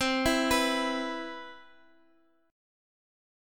E5/C chord
E-5th-C-x,x,x,5,5,7-8.m4a